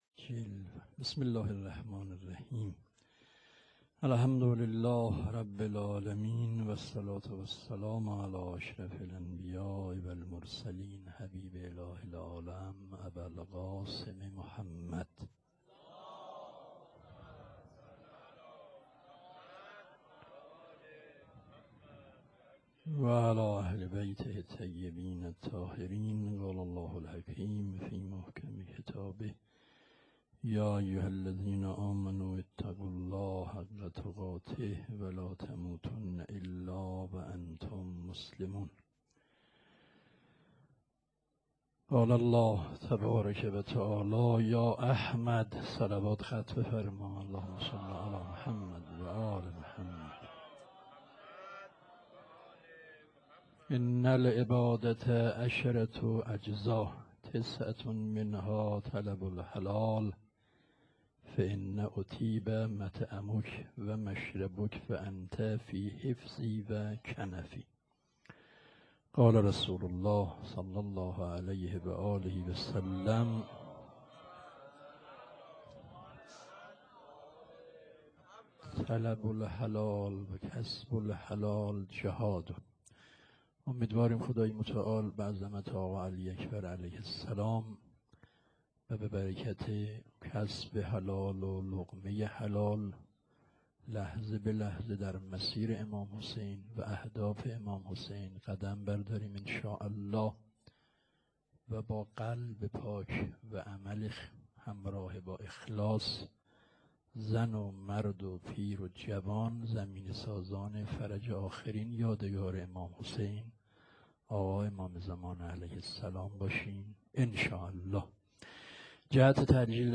شب هشتم _ سخنرانی حاج آقا فلسفی.wma